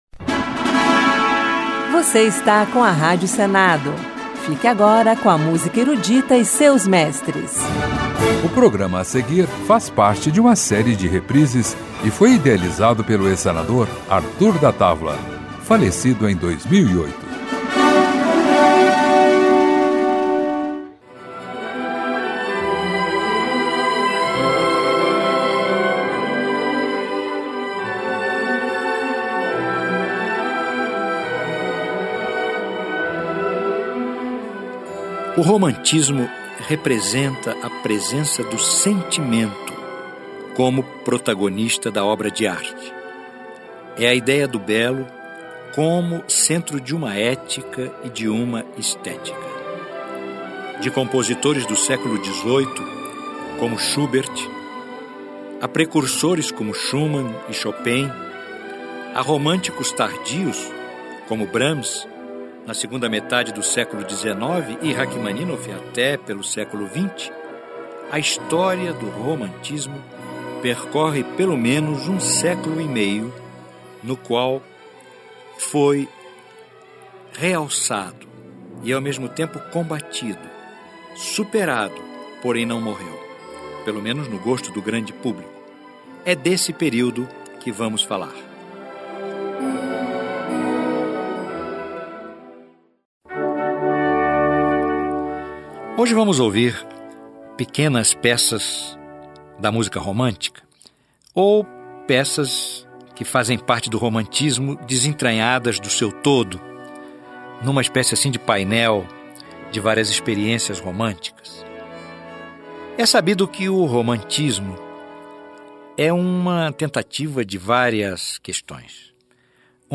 Música Erudita Período romântico